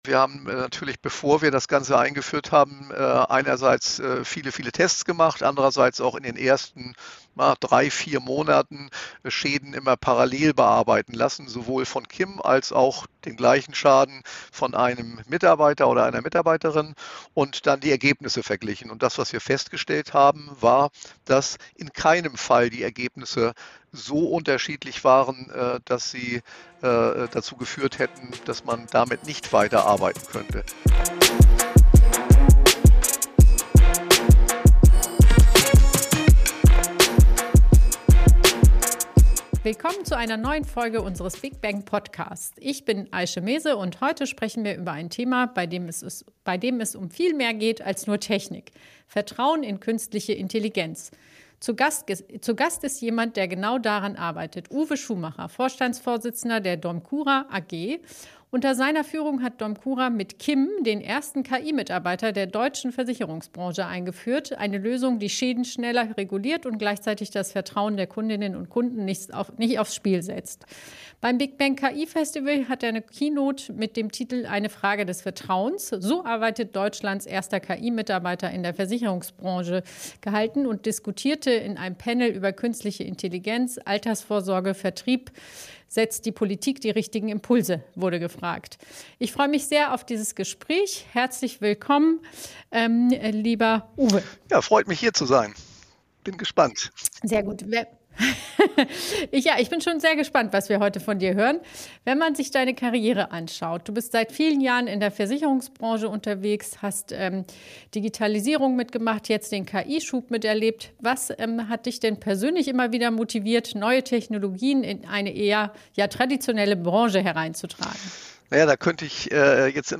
Ein Gespräch über Effizienz, Verantwortung und die Frage, wie KI Vertrauen schaffen kann, statt es zu gefährden. Neben den technologischen Aspekten geht es auch um Führung im Wandel, Akzeptanz in der Belegschaft und die Balance zwischen Automatisierung und Menschlichkeit.